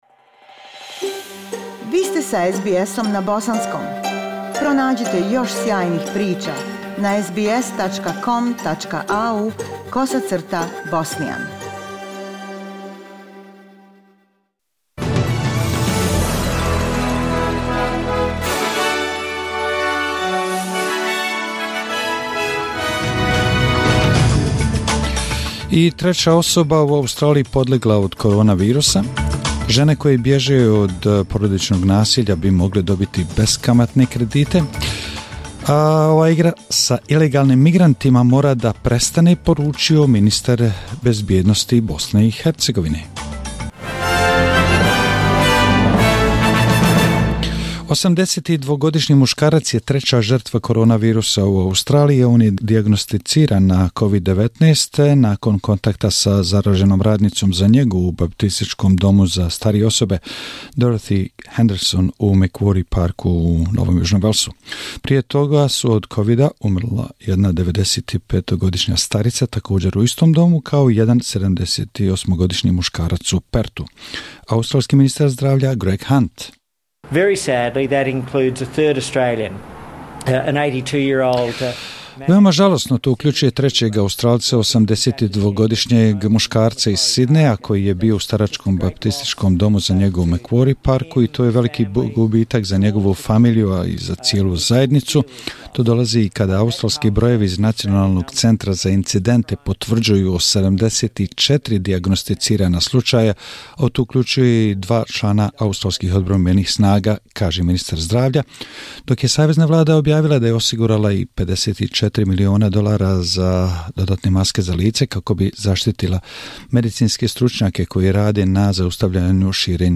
SBS Bosnian News March 8 / Vijesti za 8. mart